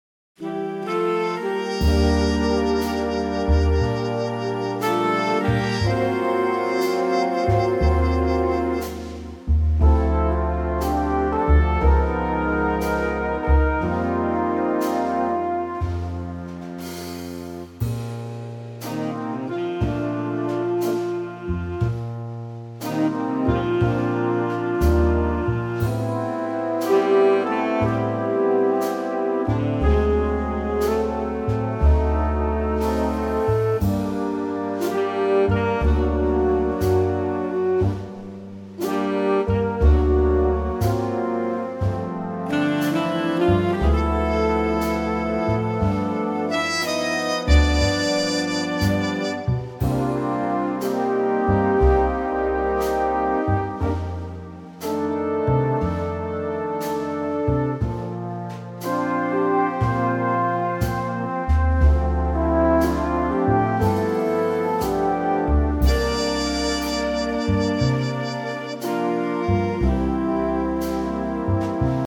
key - Bb - vocal range - C to Eb
Stunning mellow Big Band arrangement